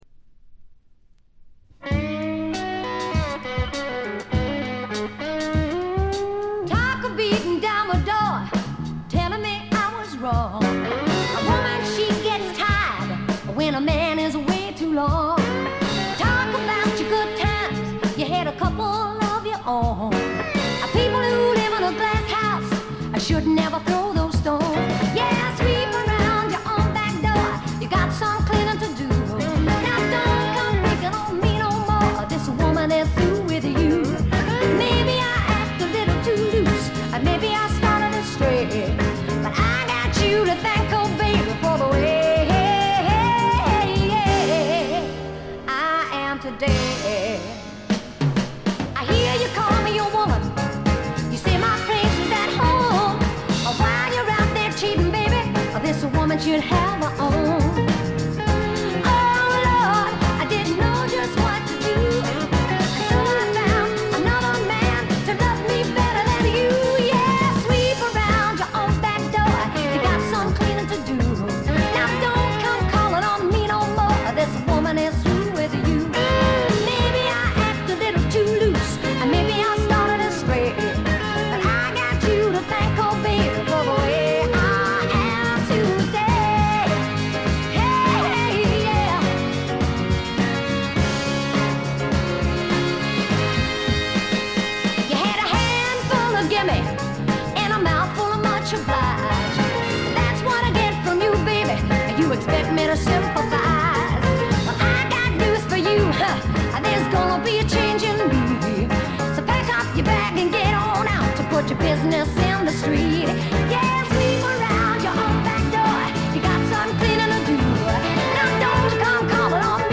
部分試聴ですが、ごくわずかなノイズ感のみ。
試聴曲は現品からの取り込み音源です。